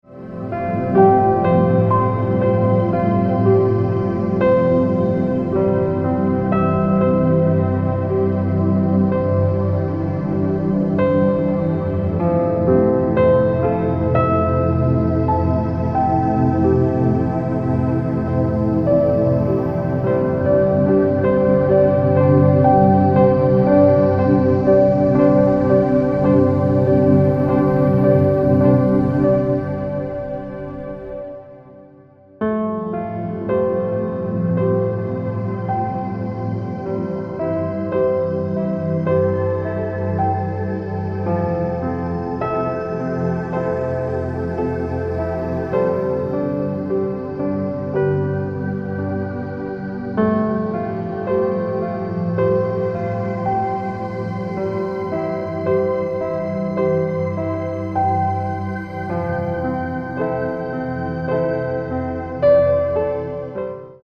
Vorschau New Age Musik